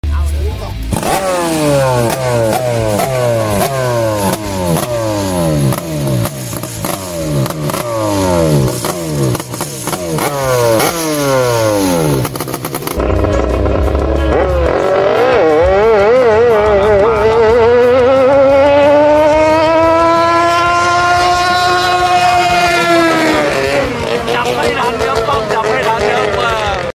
Man, I wouldn't trust being in such a shallow boat (only 1 inch above the water line) with a screaming 2-stroke driving it forward.